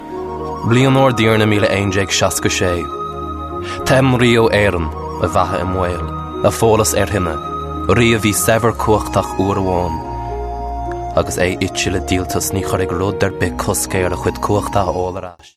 20s-30s. Male. Irish.